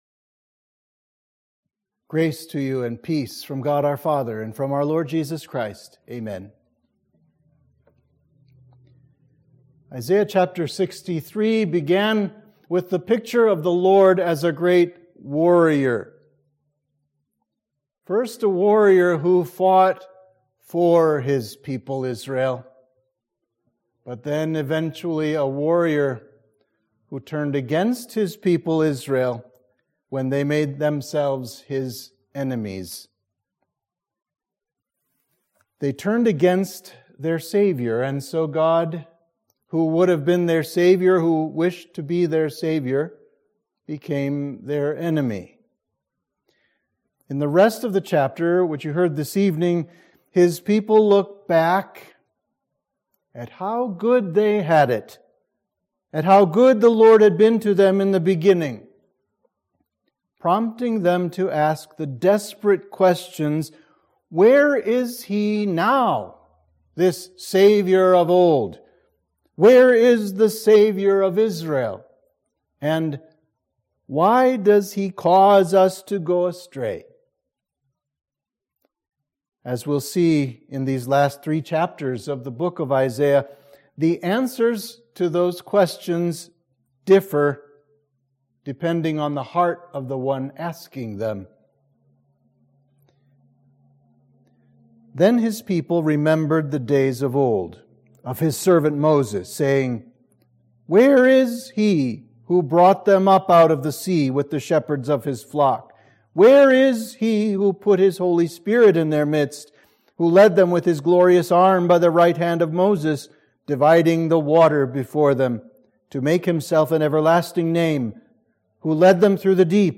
Sermon for Midweek of Trinity 23